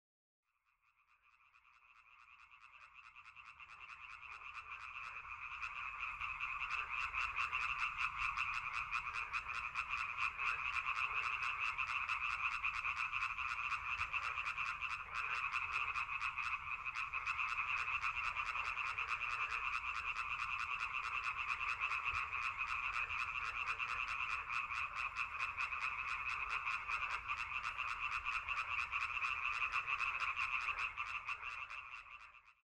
Froschkonzert (MP3)
froesche.mp3